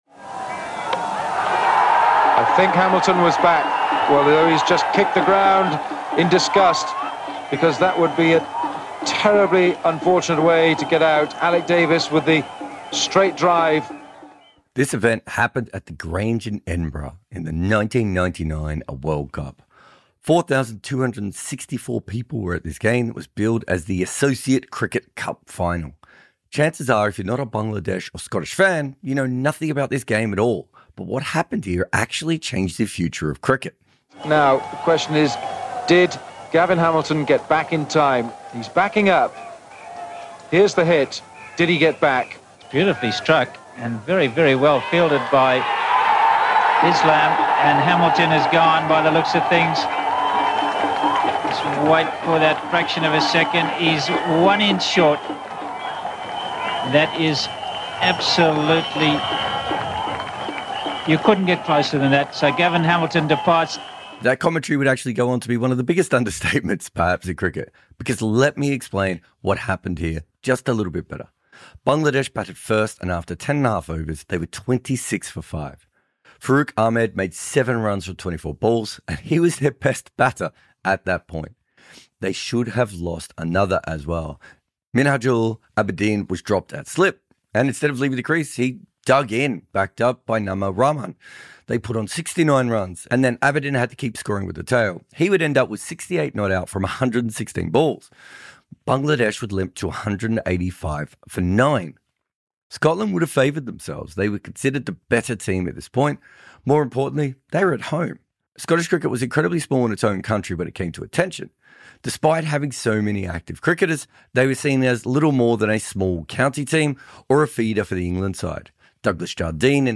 produces and narrates this series